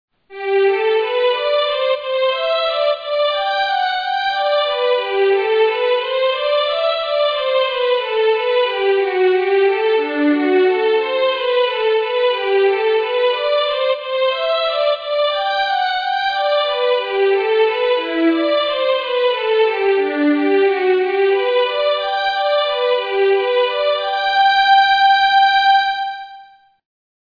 Chorale “Jesus bleibet meine Freude” from J.-S. Bach's cantata n° 147
On the score, we represent the theme using the key of G major, on a staff whose key signature includes a single sharp, so as to save writing.